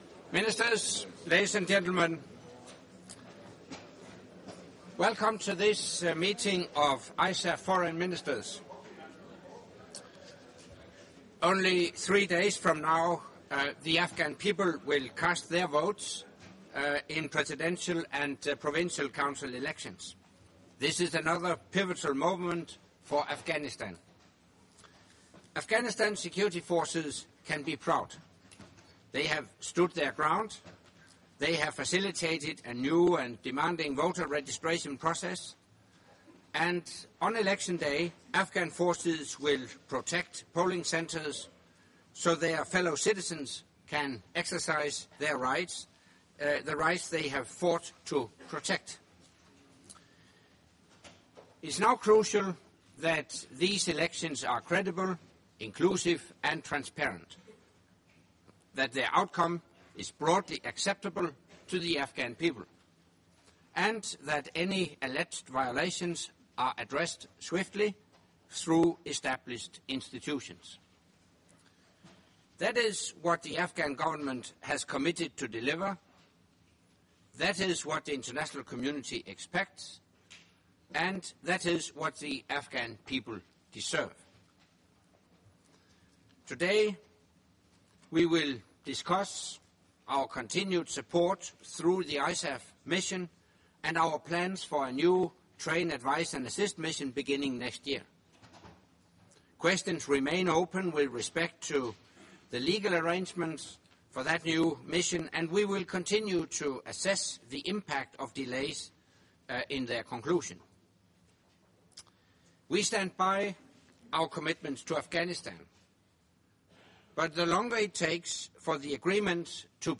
Press conference by NATO Secretary General Anders Fogh Rasmussen following the meeting of the North Atlantic Council with non-NATO ISAF contributing nations in Foreign Ministers session